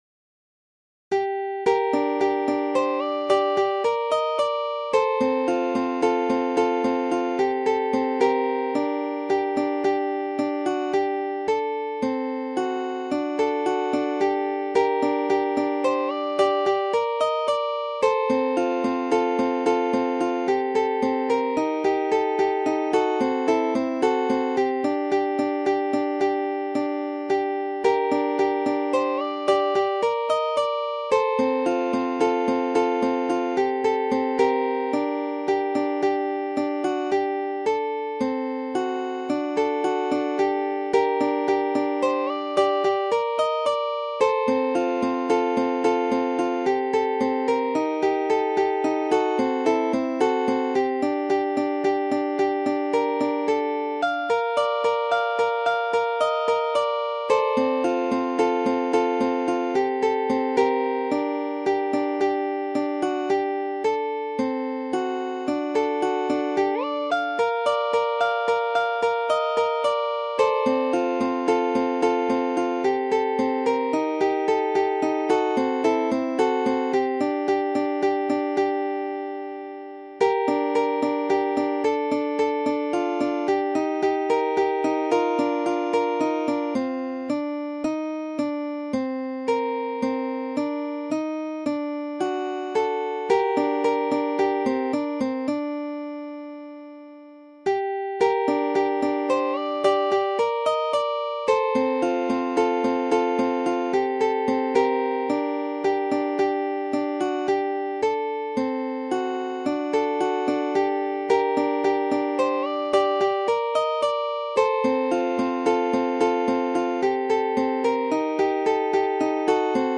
Ukulele | 乌克丽丽 | Fingerstyle | 指弹 | Solo | 独奏 | Tab | 谱
Ukulele Fingerstyle Solo Tab 乌克丽丽 指弹 独奏 谱